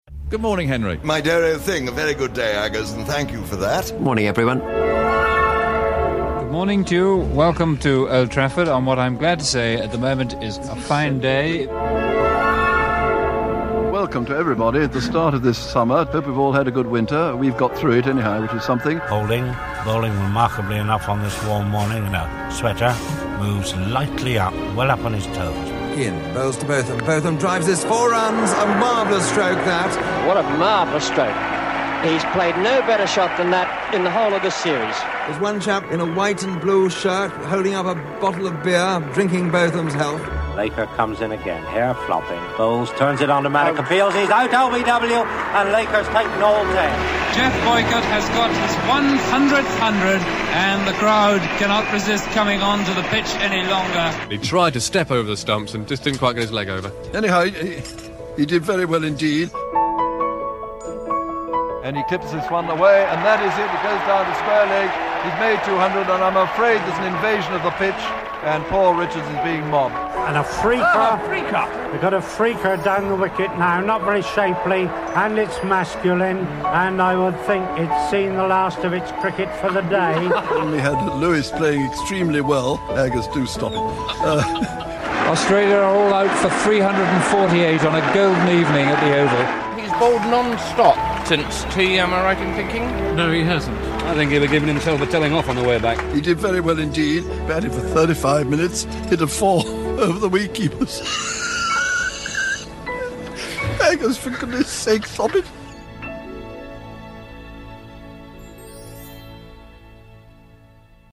Rich. Fruity. They are as warm as sunshine; and as English as cucumber sandwiches.
Witness the voices of the great cricket commentators like John Arlott, Brian Johnston, Christopher Martin-Jenkins, and Richie Benaud and Henry Blofeld.
Enjoy this great slice of sunshine from BBC 5 live’s programme ‘the Voices of Summer’ in May 2013, on the 56th anniversary of that first programme.